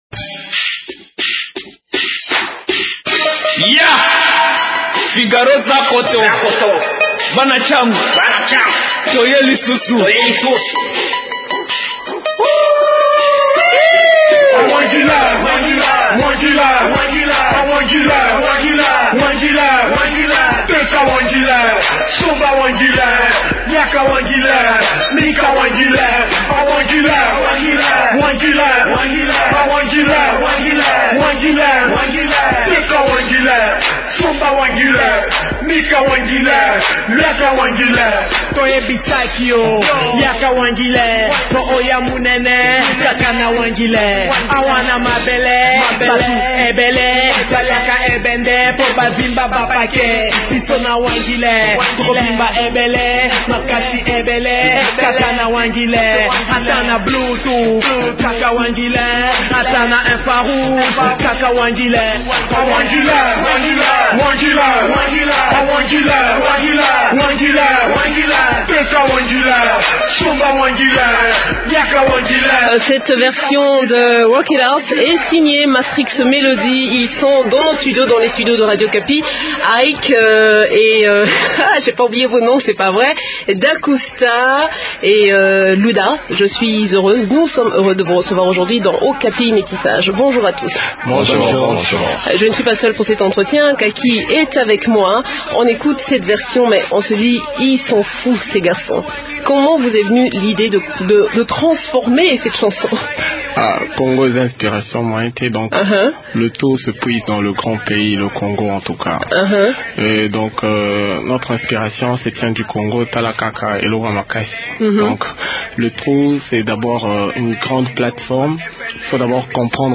parlent des débuts de ce groupe et de leur future tournée dans cet entretien.